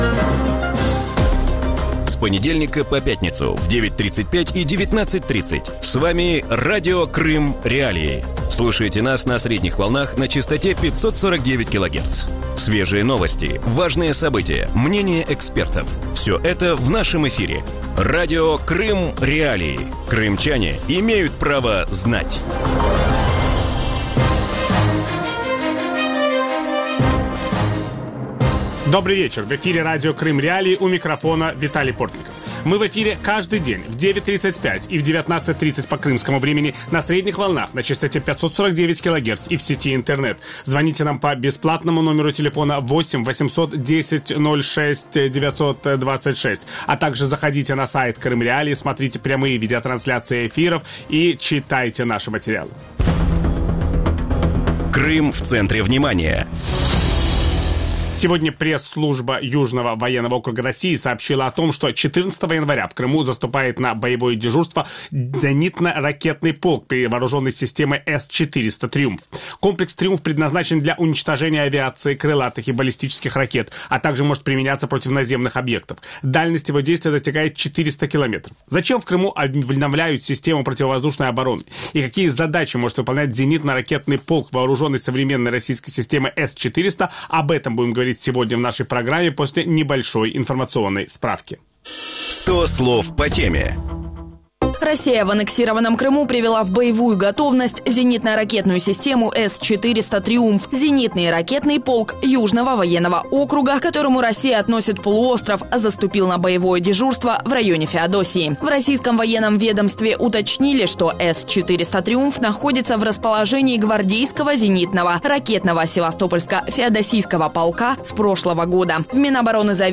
В вечернем эфире Радио Крым.Реалии говорят о заступлении на боевое дежурство в Крыму ракетного полка с системой ПВО С-400 «Триумф».
Ведущий: Виталий Портников.